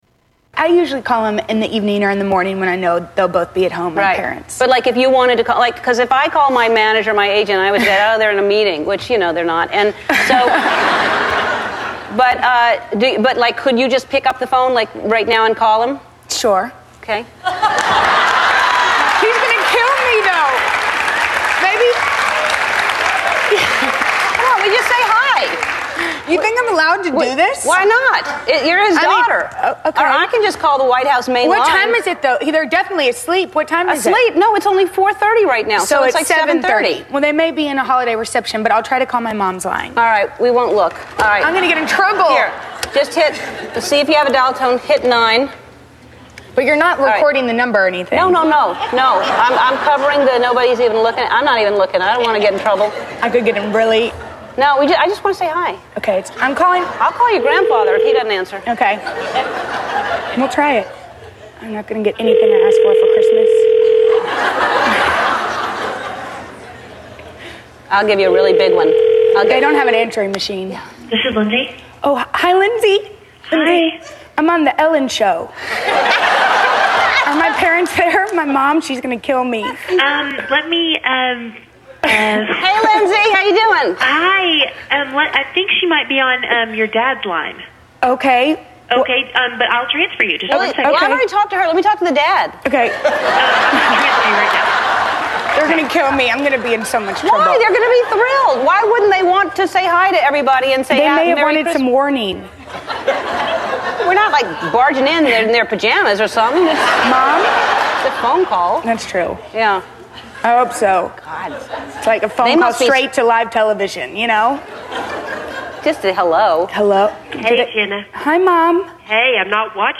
Ellen and Jenna Bush call the President
Tags: Comedian Celebrity Ellen DeGeneres TEDS Funny